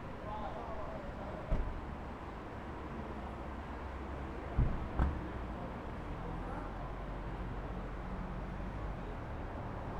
Environmental
Streetsounds
Noisepollution